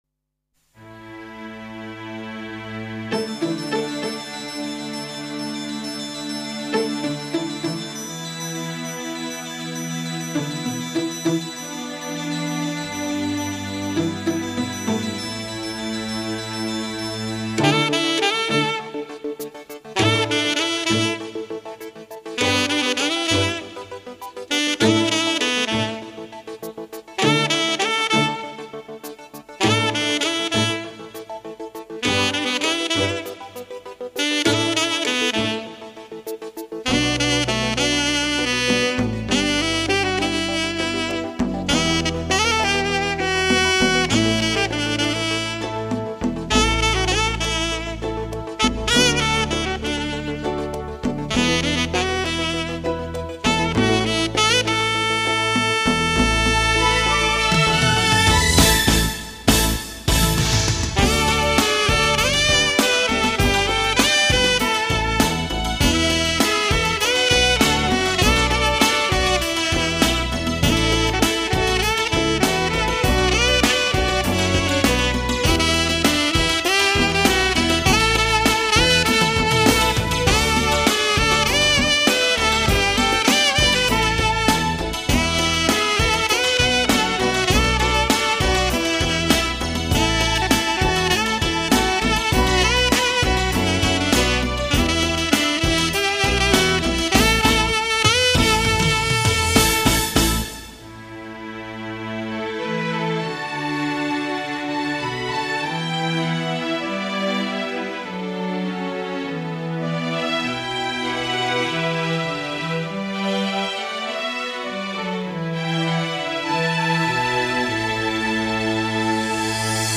悠悠的萨克斯，奏出的是那淡淡的感伤，浓浓的思绪；
沏上一杯浓香的咖啡，闭上双眼，耳边飘起怀旧与性感的萨克斯。